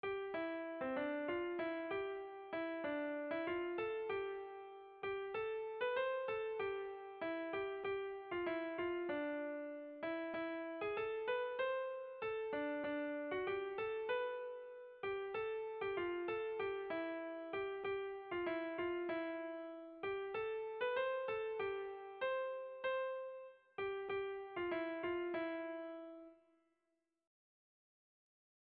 Air de bertsos - Voir fiche   Pour savoir plus sur cette section
Zortziko txikia (hg) / Lau puntuko txikia (ip)
ABDB